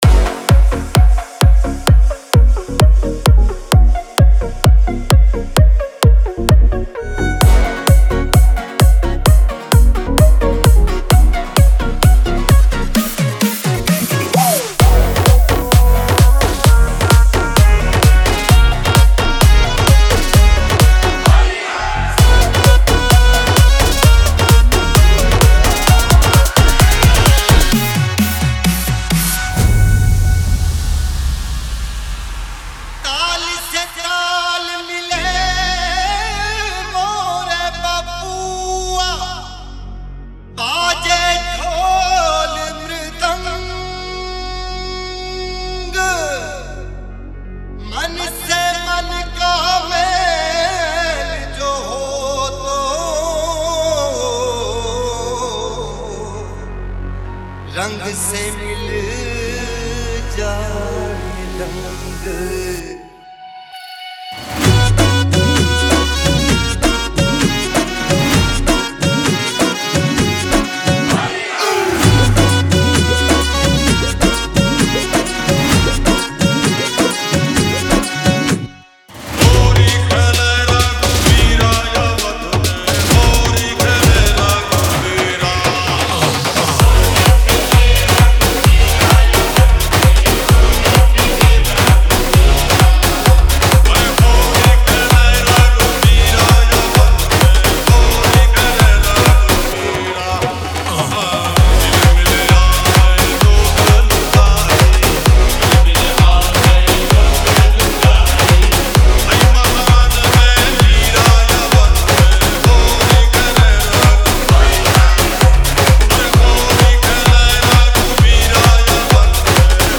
HOLI SPECIAL DJ SONG